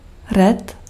Ääntäminen
Synonyymit badigoince Ääntäminen France: IPA: [lɛvʁ] Haettu sana löytyi näillä lähdekielillä: ranska Käännös Ääninäyte Substantiivit 1. ret {m} 2. pysk Suku: f .